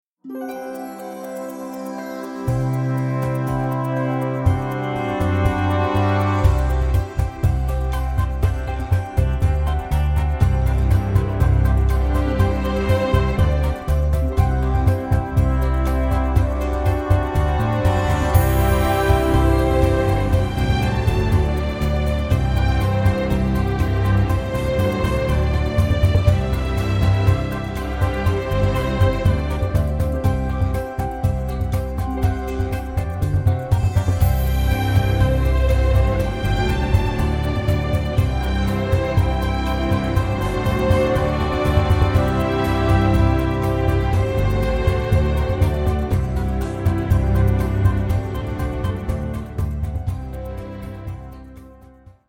a delicate and quirky score